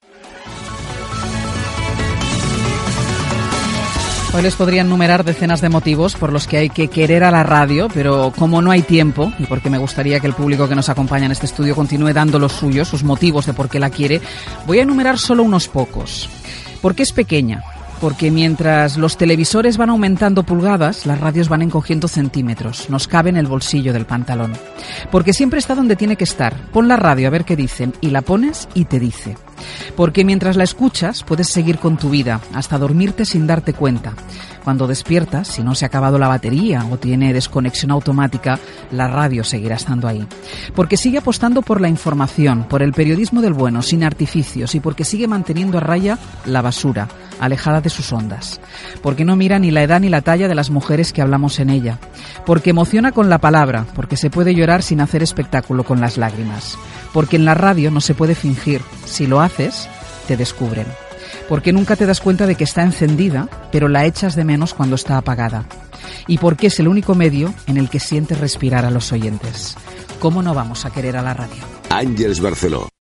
Àngels Barceló dóna diversos motius per estimar la ràdio en el Dia Mundial de la Ràdio
Info-entreteniment